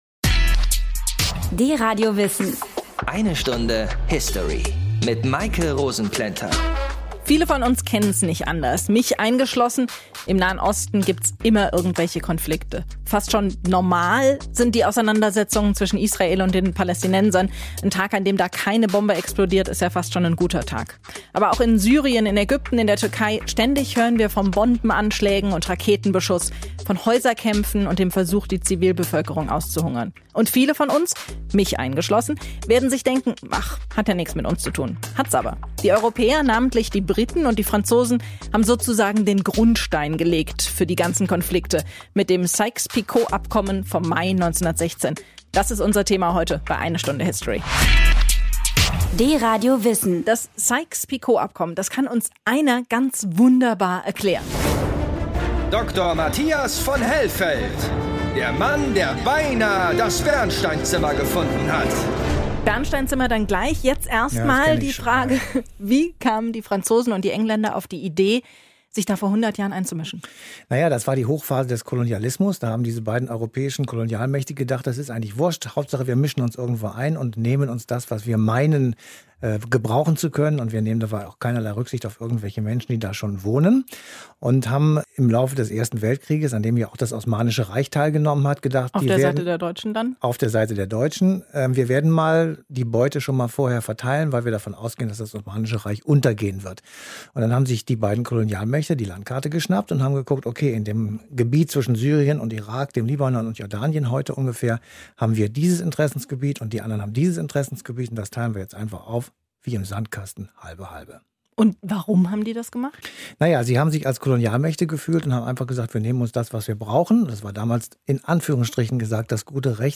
(Sendungsmitschnitt von DRadio Wissen)